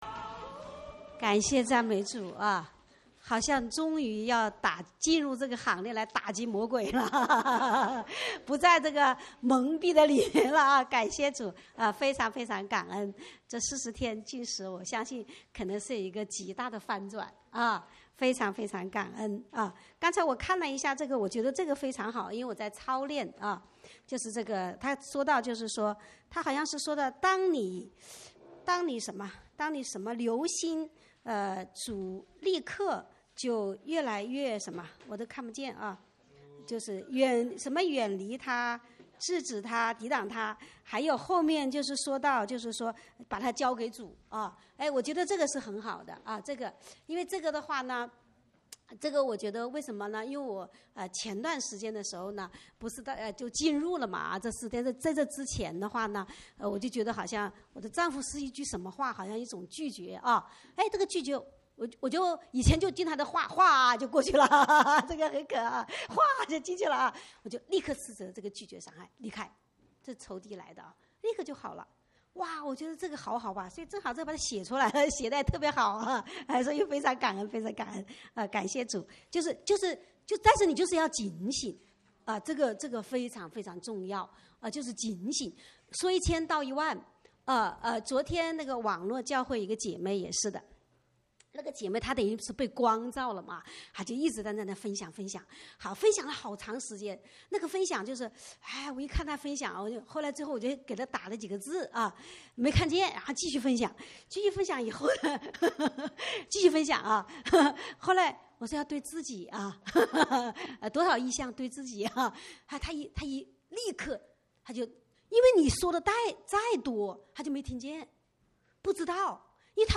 主日恩膏聚会录音（2016-09-11）